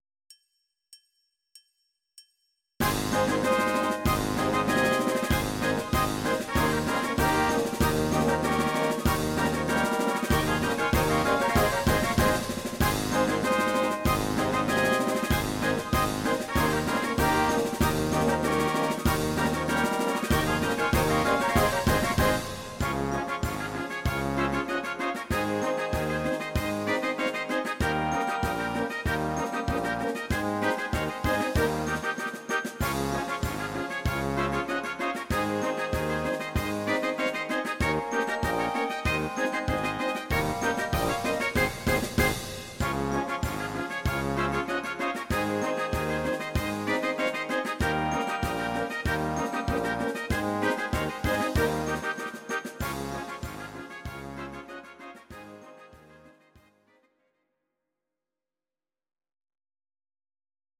Audio Recordings based on Midi-files
Jazz/Big Band, Instrumental, Traditional/Folk